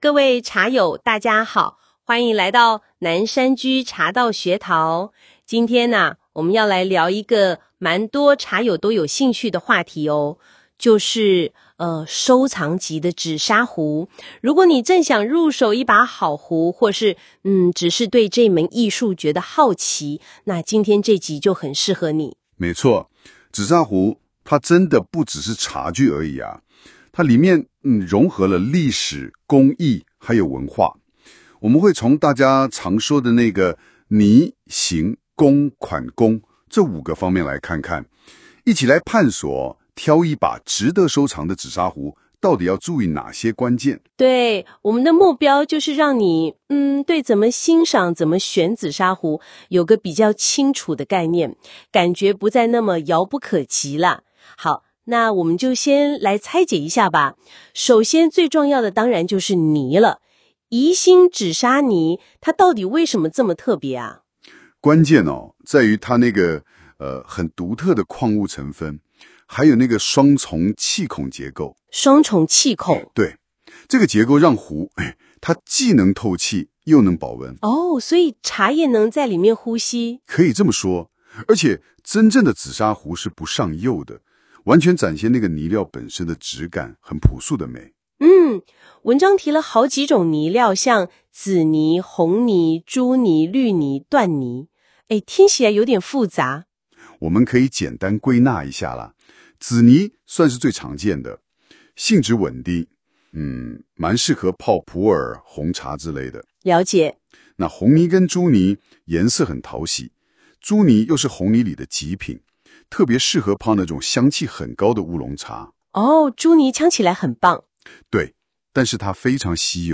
【語音導讀】紫砂壺挑選指南：泥、形、工、款、功（7分29秒）